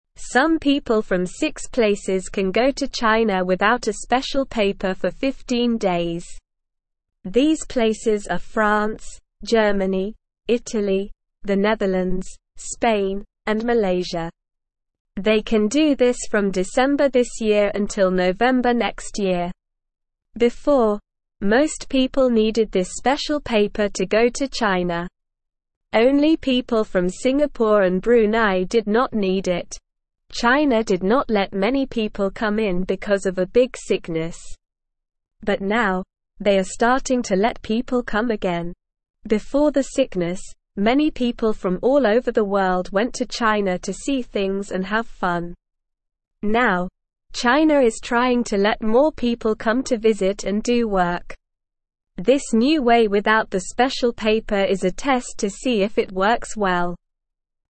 Slow
English-Newsroom-Beginner-SLOW-Reading-China-Allows-Visitors-from-Six-Countries-Without-Special-Paper.mp3